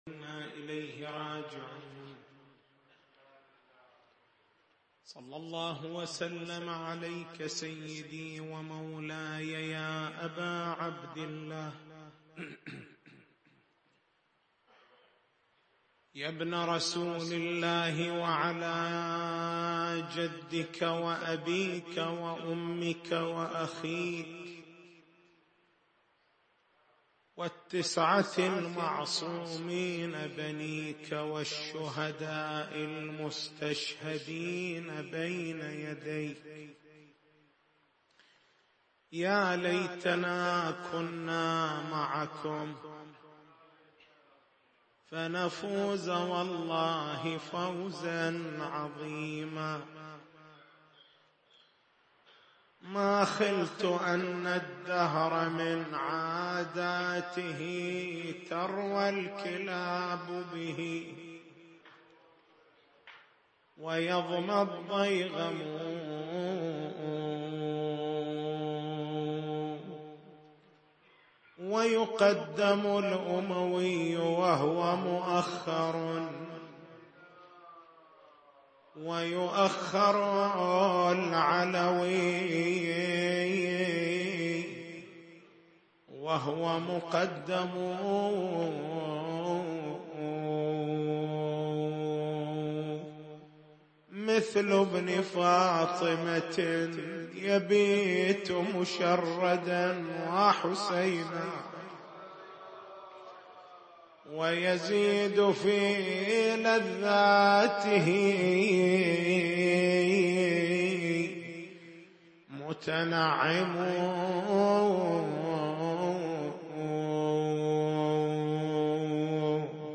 تاريخ المحاضرة: 16/01/1439 نقاط البحث: ما معنى خروج الإمام من المدينة خائفًا؟